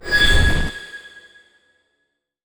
magic_flame_of_light_01.wav